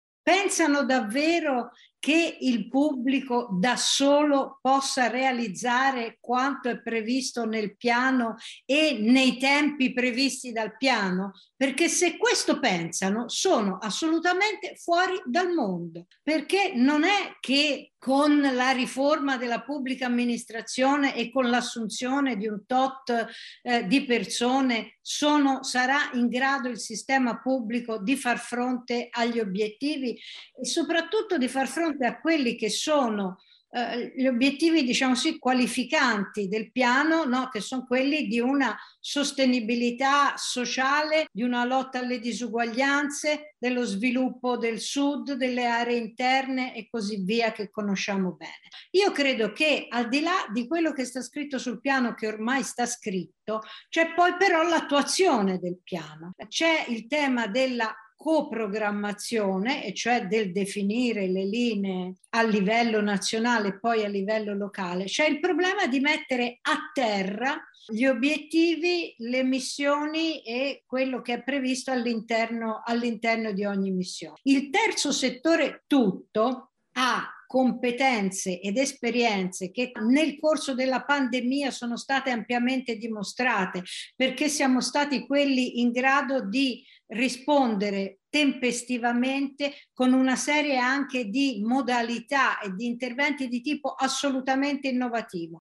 Bentornati all’ascolto del Grs Week.